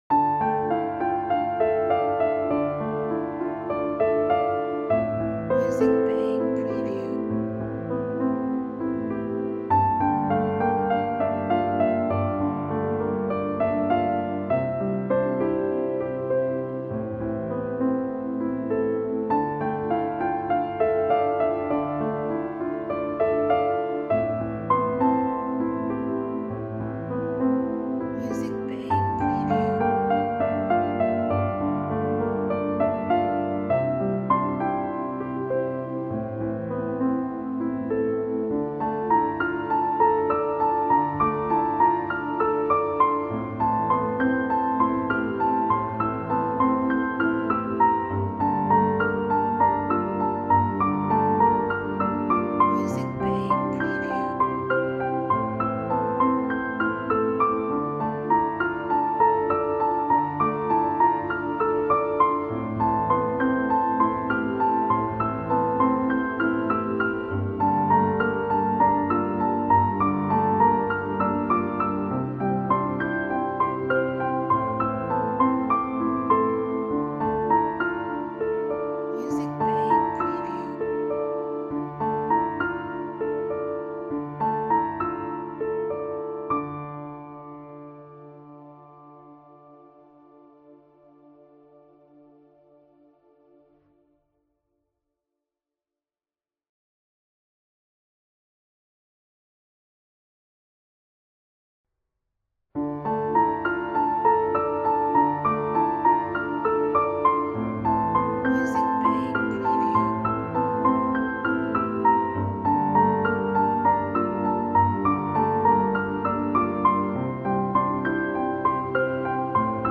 sad – all sad and nostalgic music for videos and films